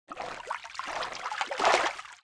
fishing_catch.wav